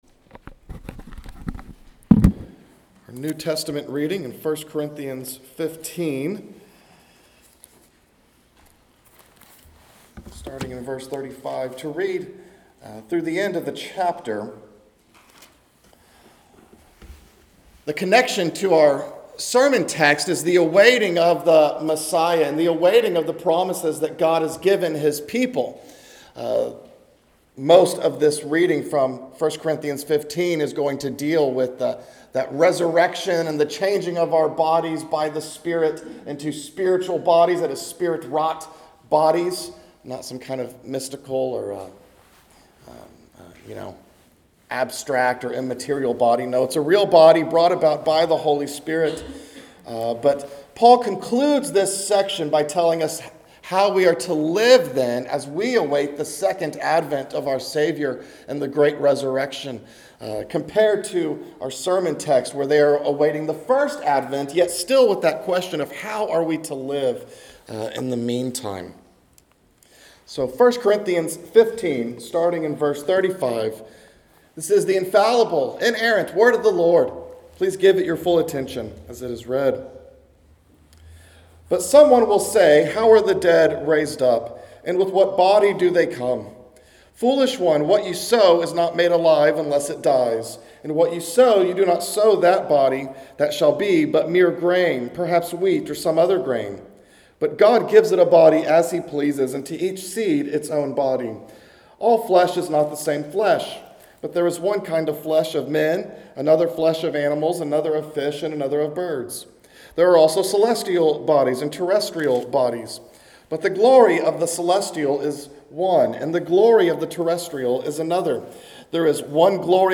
A message from the series "Zechariah."